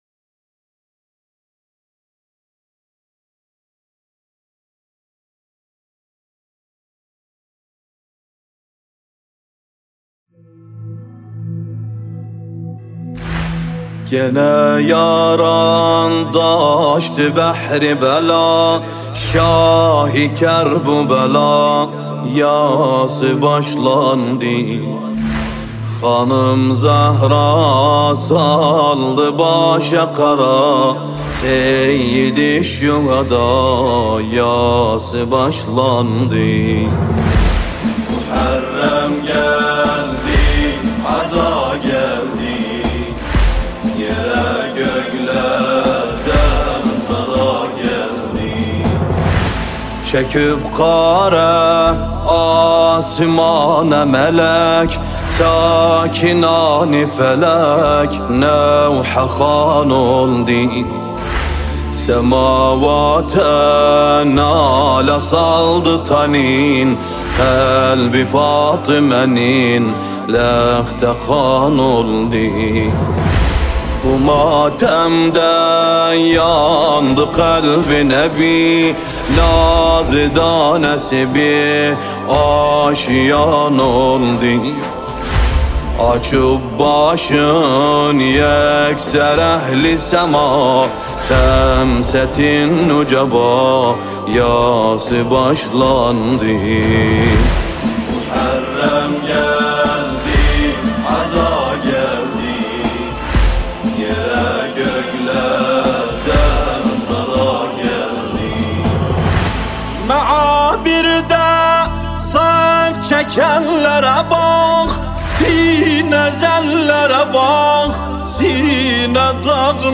نوحه محرم
مداحی ترکی جدید